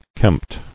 [kempt]